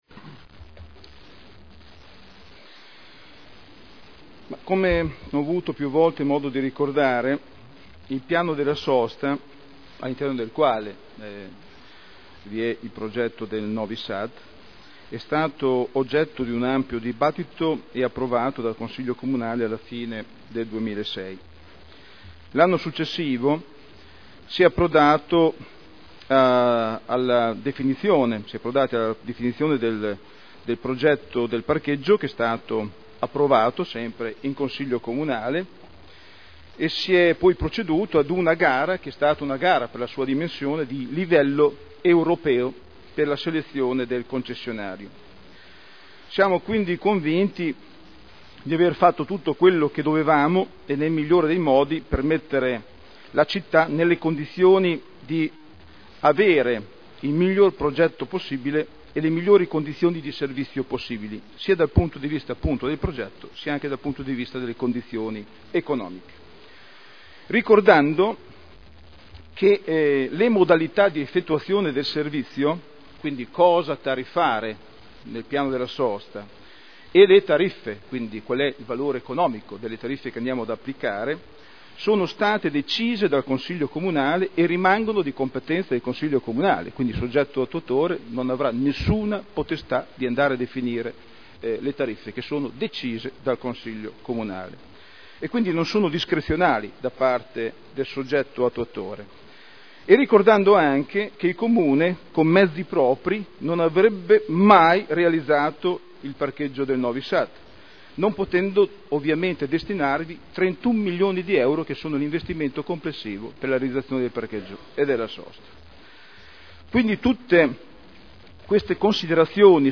Daniele Sitta — Sito Audio Consiglio Comunale